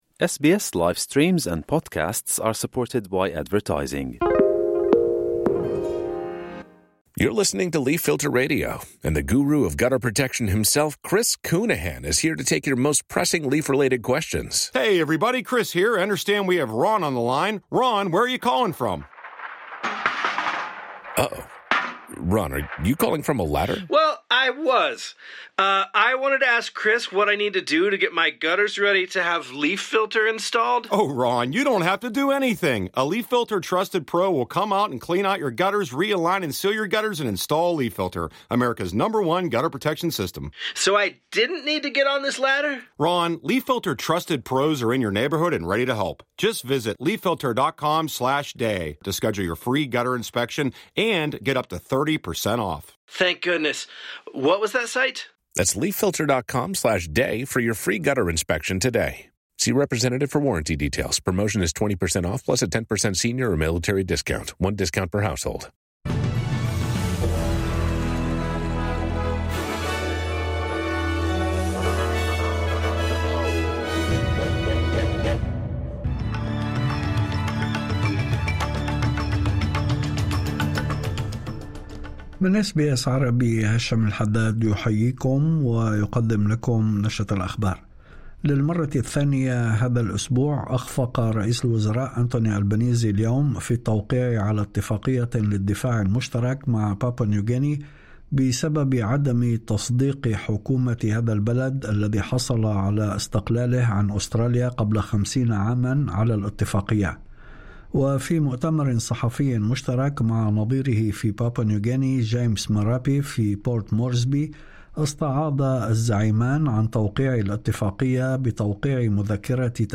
نشرة أخبار الظهيرة 17/09/2025